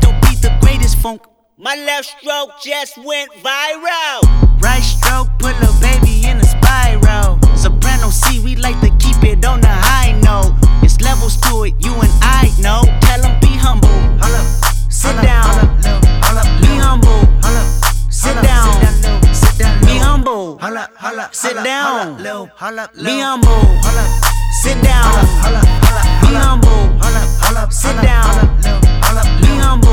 • Hip-Hop